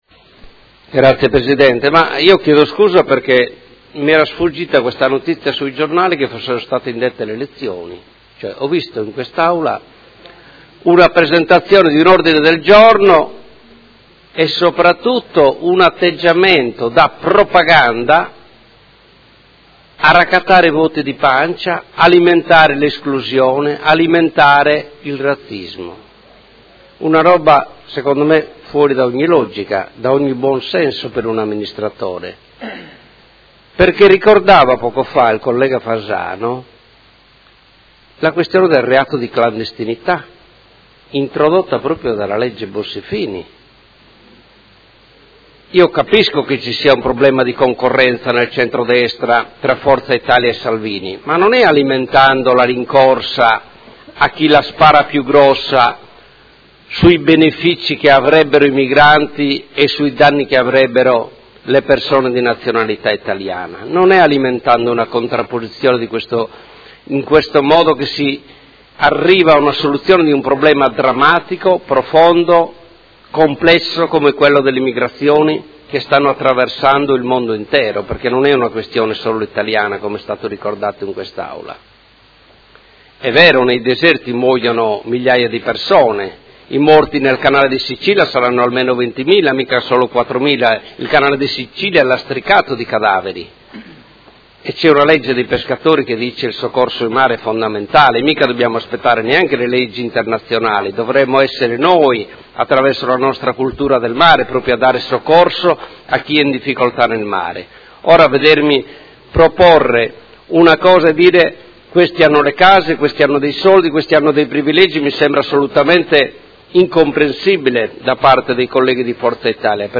Seduta del 16/02/2017. Dibattito su Mozione presentata dal Gruppo Forza Italia avente per oggetto: Le nostre proposte per l’emergenza immigrazione e per la sicurezza